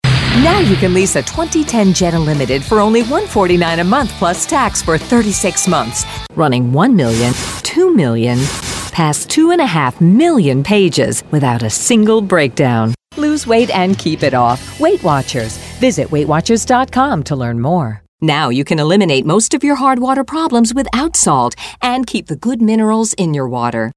Hard Sell